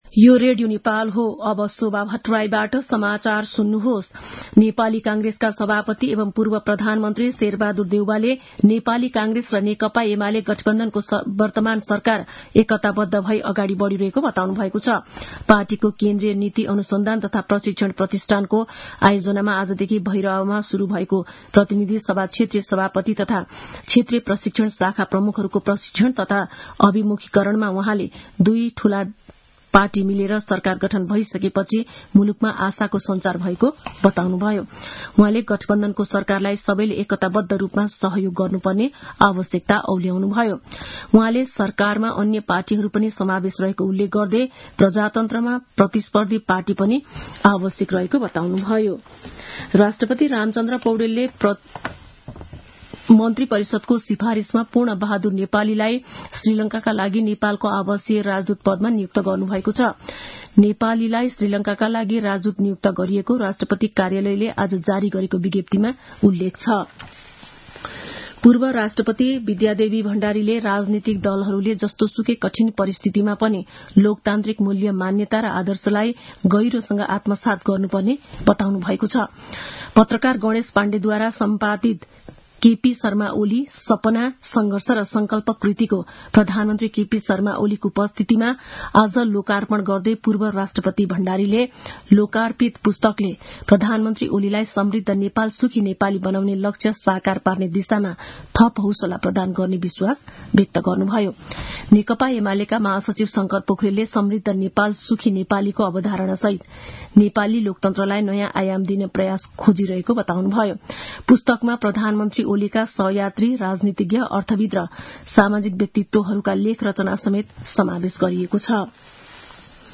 दिउँसो ४ बजेको नेपाली समाचार : २० फागुन , २०८१
4-pm-news.mp3